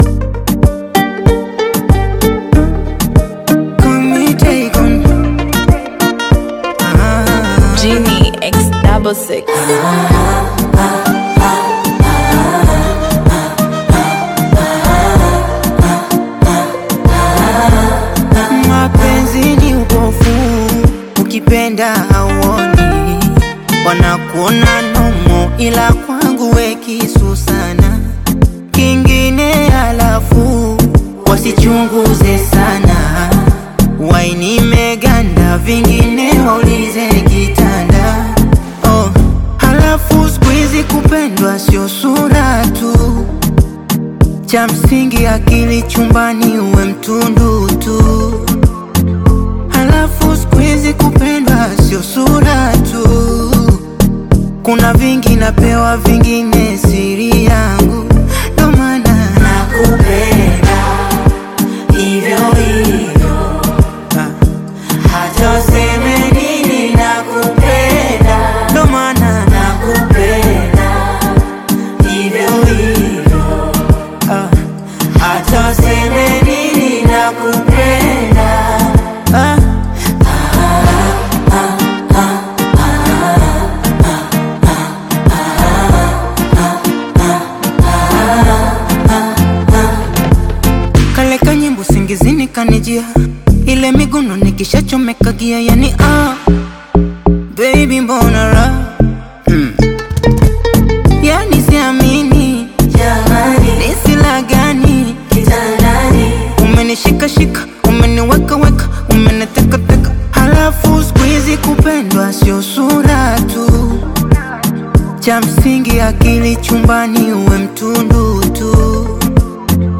Bongo Flava
a love song